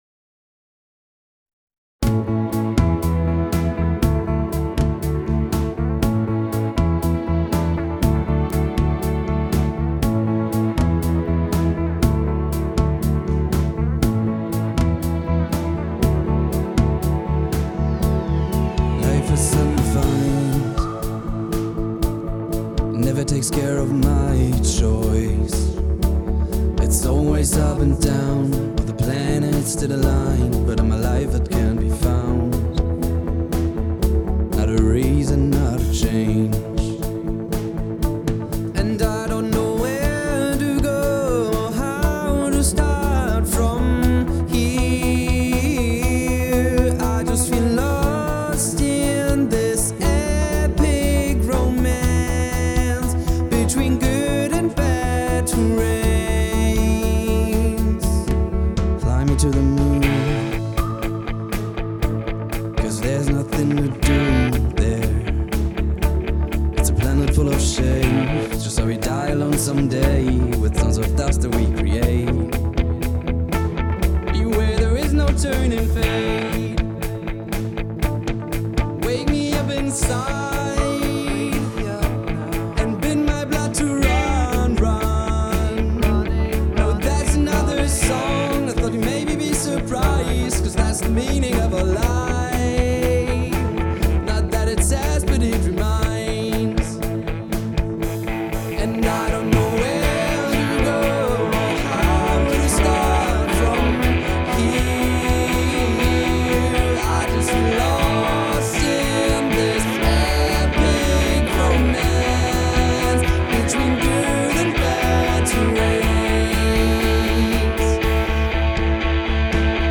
being and recording in a professional studio